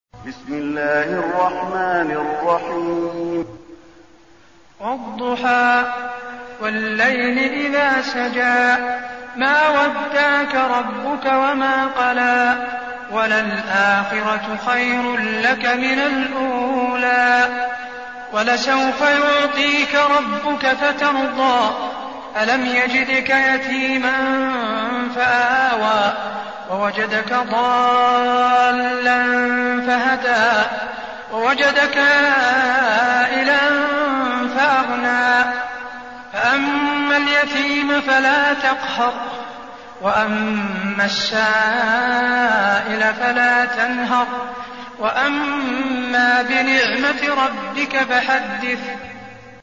المكان: المسجد النبوي الضحى